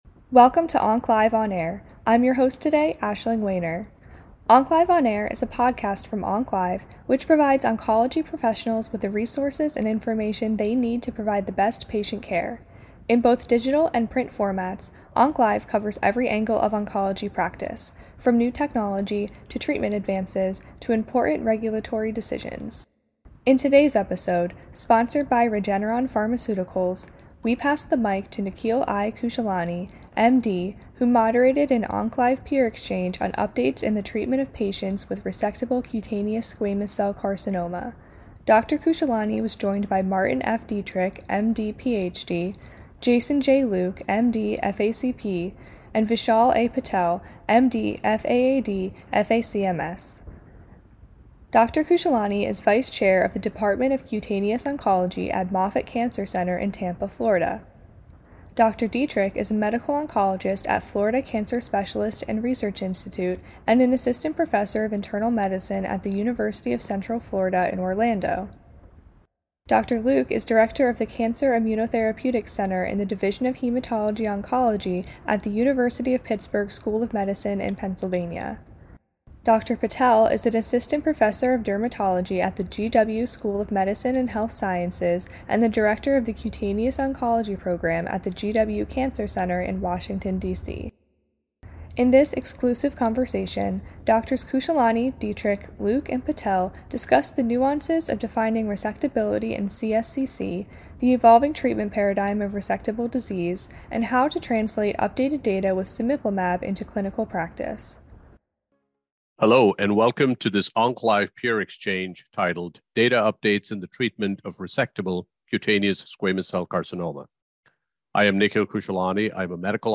CSCC Experts Discuss Considerations for Determining Resectable Disease and Translating Data Into Practice